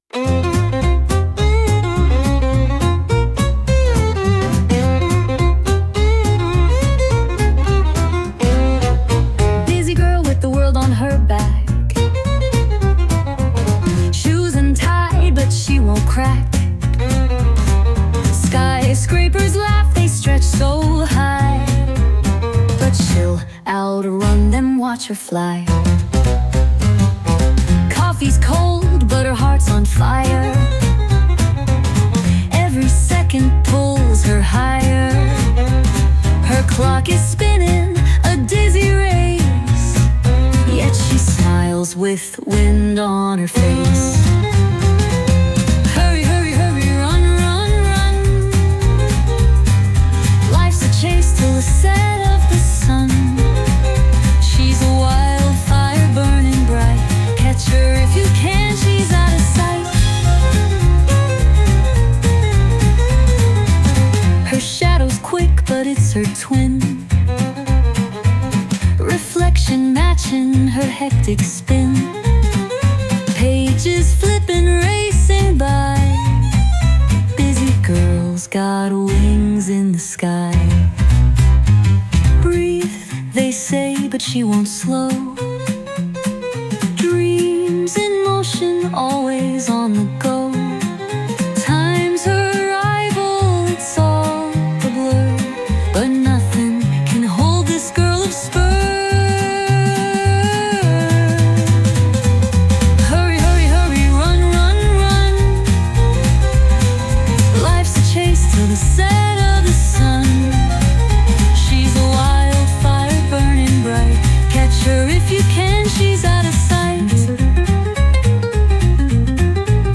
With Vocals / 歌あり
Featuring a catchy vocal and a danceable tempo,
元気な女の子がバタバタと急いでいる様子をイメージした、楽しくてちょっとコミカルな一曲！
歌声が印象的で、踊りやすいテンポが特徴の新体操用ミュージックです💃✨